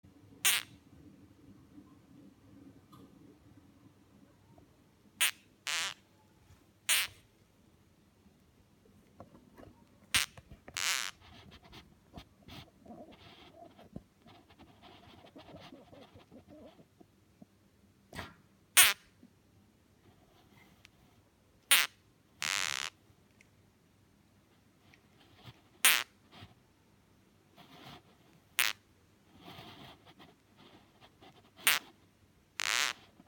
Звук геккона при нападении